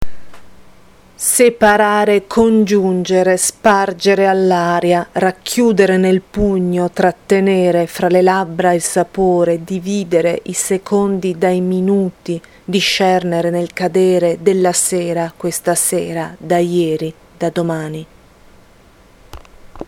Una poesia di Goliarda Sapienza letta da